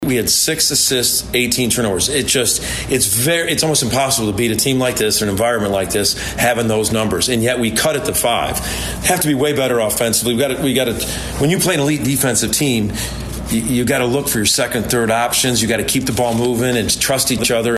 Oklahoma head coach Porter Moser after the loss.
x-postgame moser interview (3).mp3